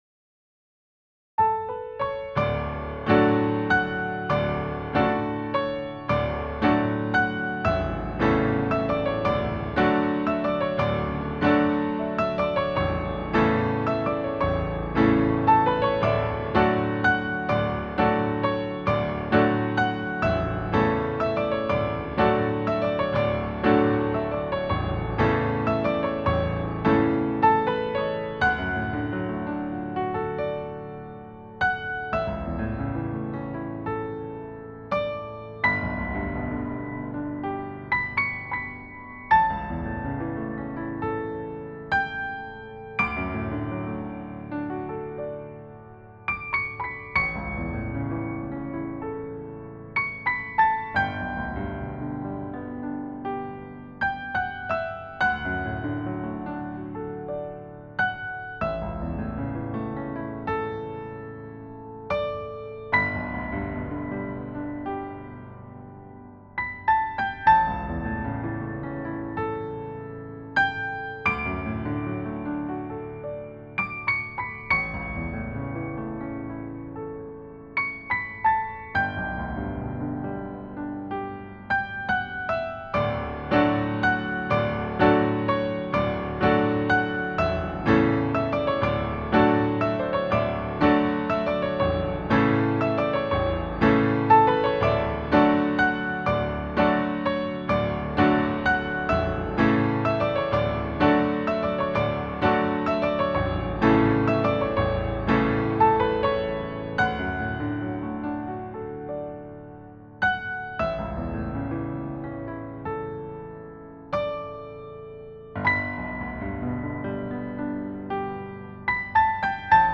prelude_in_d_major.mp3